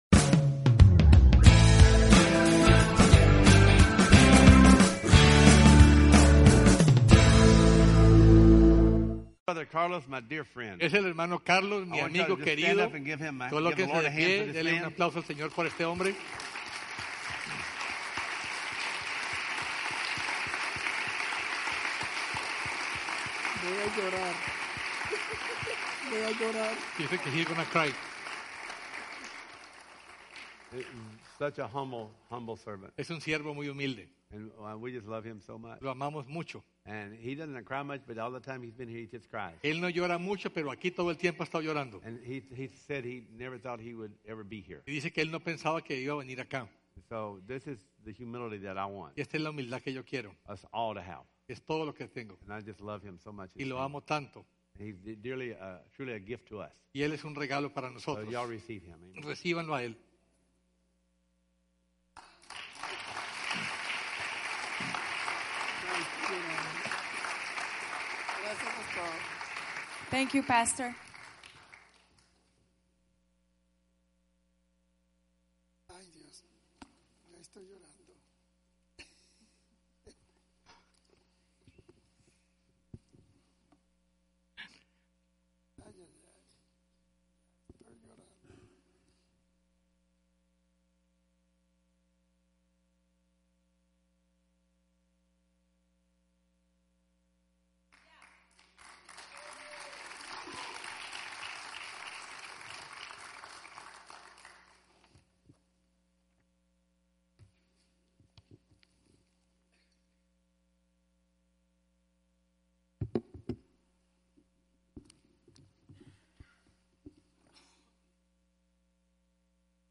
Series: Guest Speakers Service Type: Sunday Service Download Files Notes « From the Beginning to the End with JESUS!